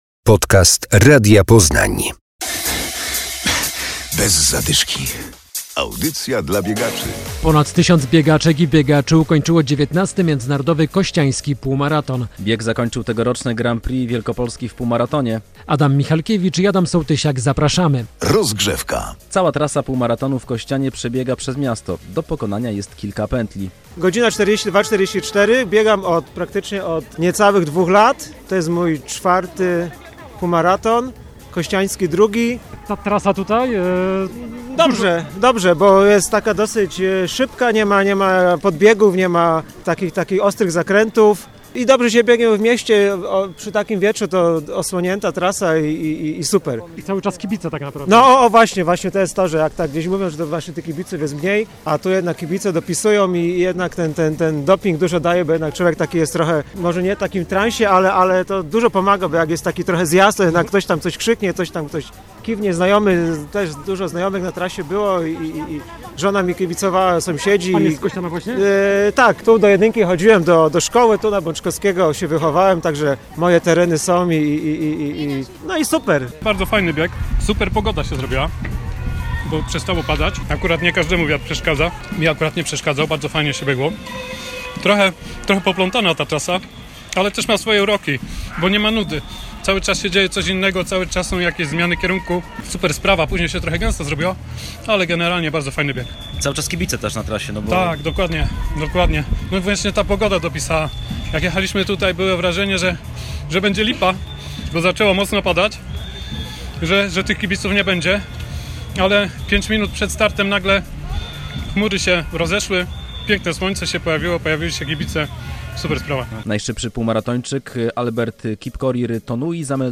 Relacja z półmaratonu w Kościanie.